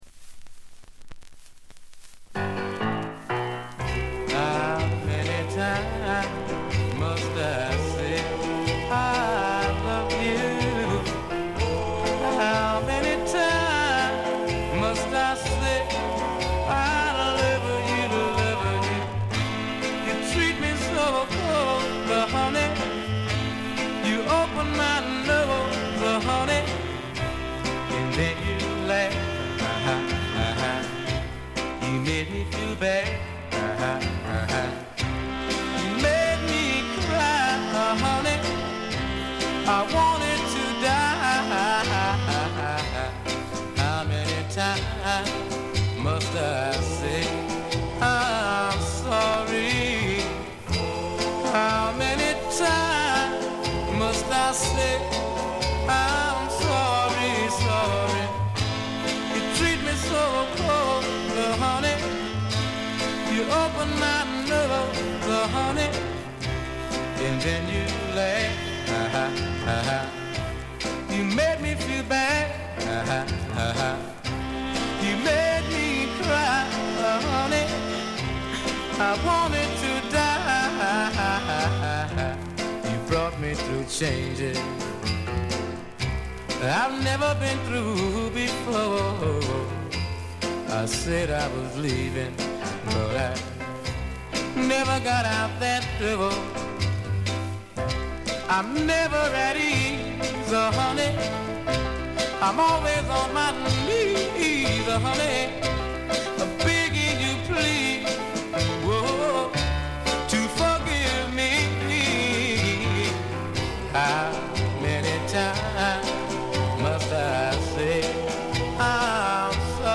「くもり」のためか全体に軽微なバックグラウンドノイズが出ますが鮮度は良好です。ところどころでチリプチ。
試聴曲は現品からの取り込み音源です。